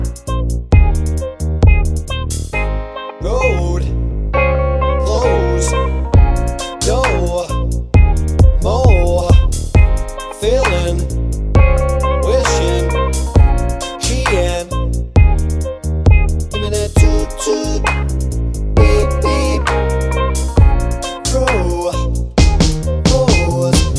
Remix With No Backing Vocals Pop (1990s) 3:01 Buy £1.50